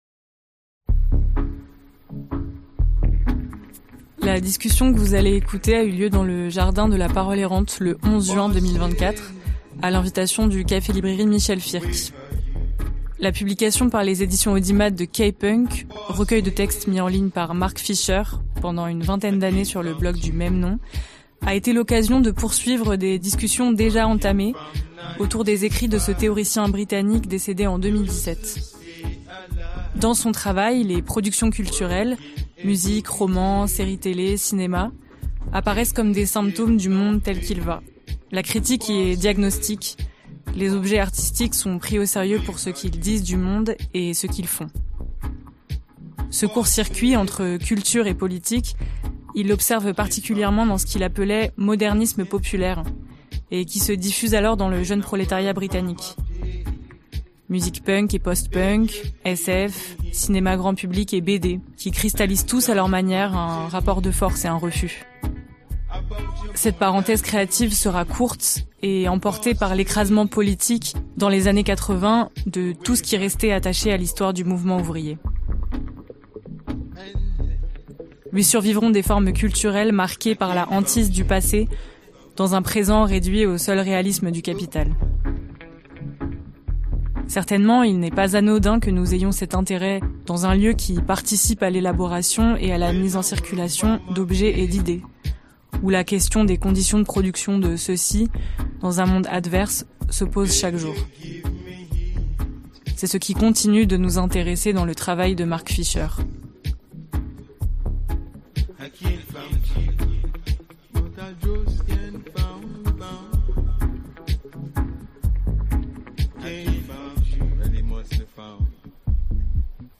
La rencontre dont est tiré l’enregistrement ci-dessous a eu lieu le 11 juin 2025* à l’invitation du Café-Librairie Michèle Firk dans La Parole Errante, lieu auto-organisé à Montreuil en Seine-Saint-Denis.
Parmi les nombreuses réalités qu’accueille cet espace, un collectif anime un studio radio qui capture et produit de nombreux audios, dont celui-ci.
L'entretien ci-dessous offre une introduction à cette œuvre unique.